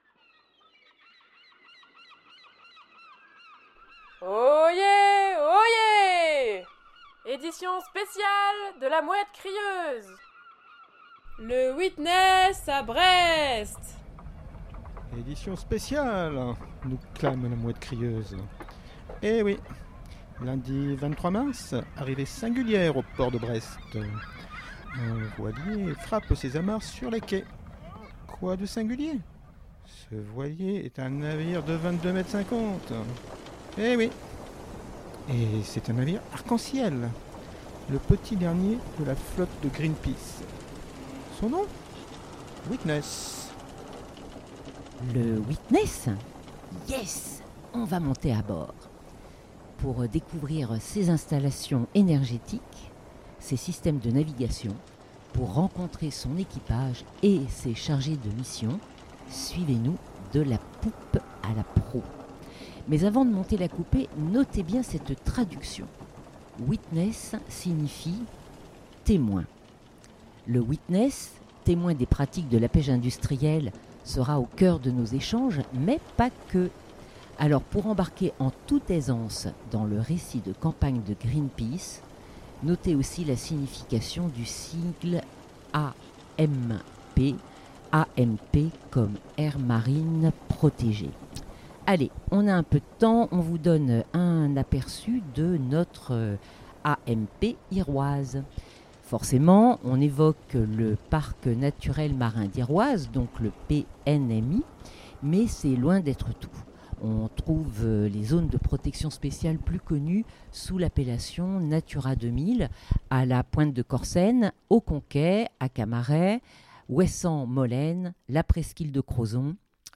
La question principale abordée sur le pont du Witness est simple, elle concerne le mot « protégée ». Quels types et quels niveaux de protection les gestionnaires publics de nos aires marines protégées soutiennent-ils ?